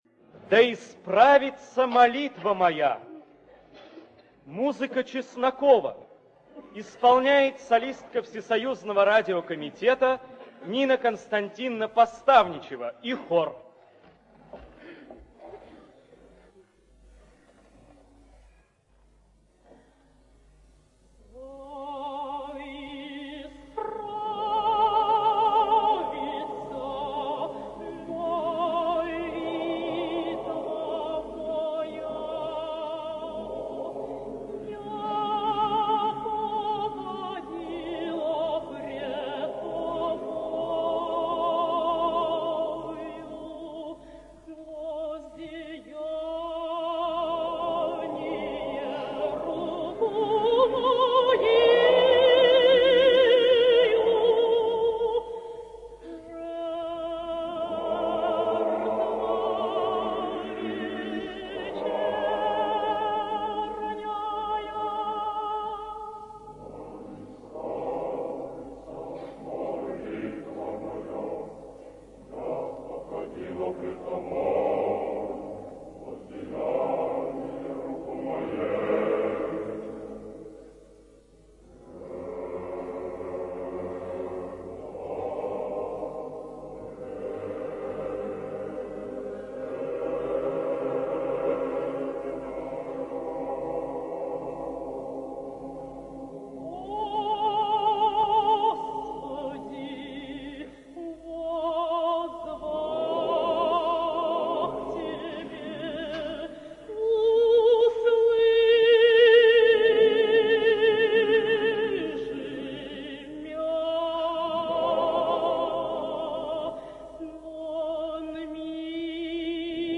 В сопровождении хора